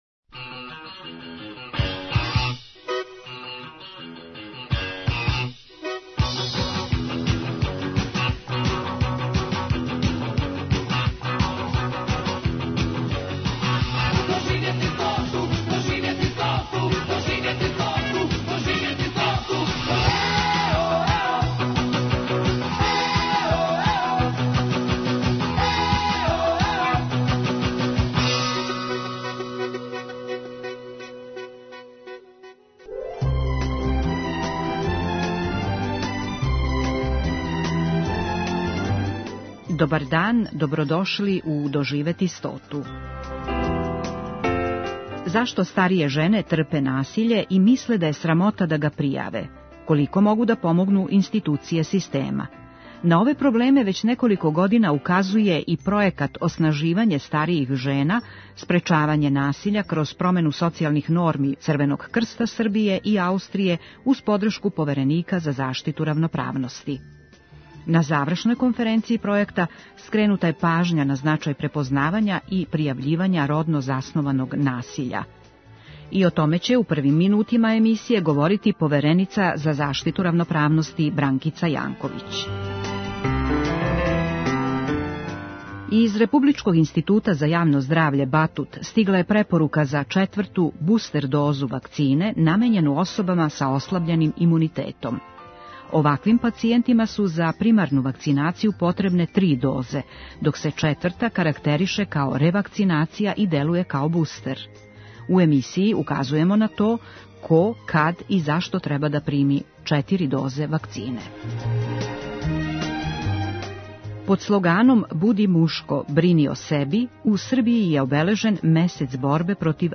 И о томе ће у првим минутима емисије говорити повереница за заштиту равноправности Бранкица Јанковић.
Емисија "Доживети стоту" Првог програма Радио Београда већ двадесет четири године доноси интервјуе и репортаже посвећене старијој популацији.